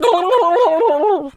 turkey_ostrich_hurt_gobble_07.wav